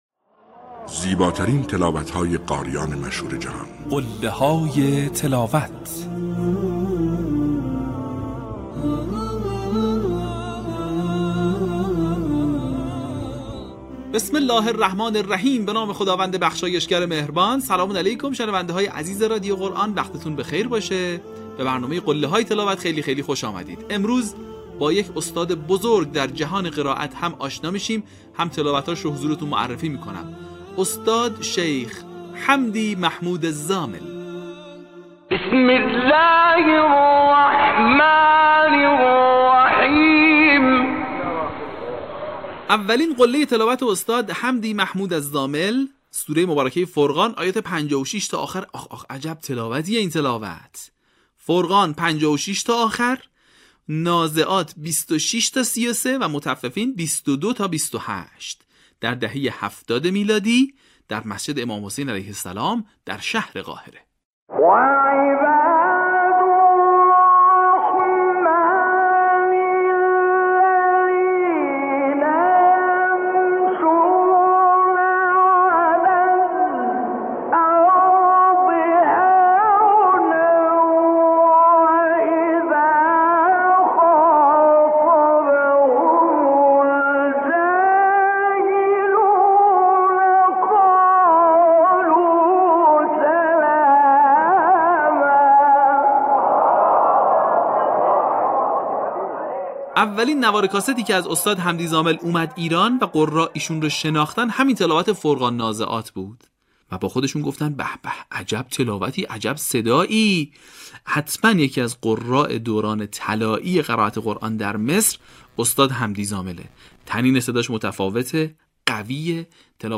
در قسمت چهل‌وپنجم فراز‌های شنیدنی از تلاوت‌های به‌یاد ماندنی استاد حمدی محمود الزامل را می‌شنوید.
برچسب ها: حمدی محمود زامل ، قله های تلاوت ، فراز ماندگار ، تلاوت تقلیدی